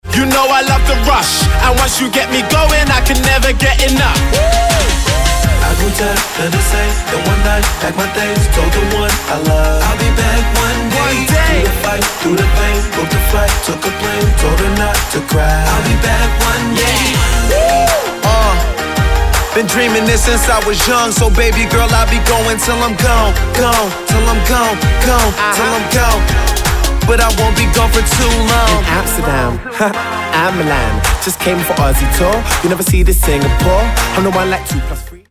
je londýnsky rapper narodený v Nigérii.